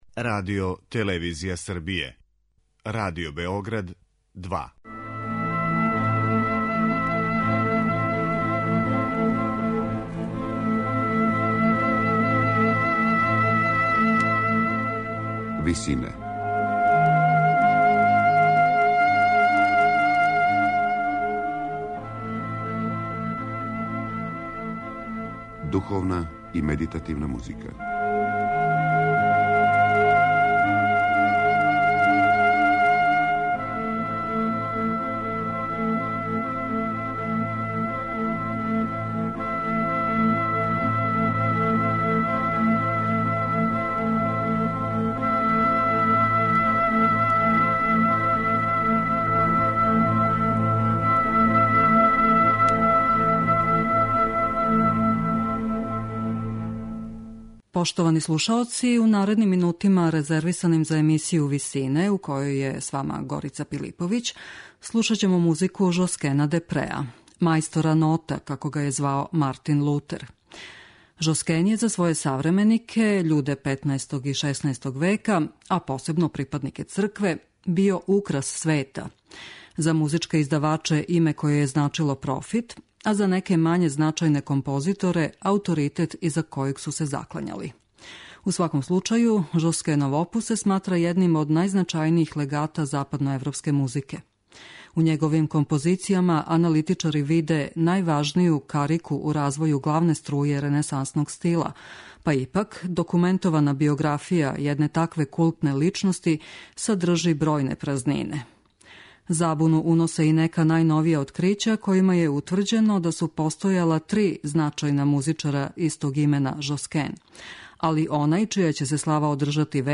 Емисија духовне и медитативне музике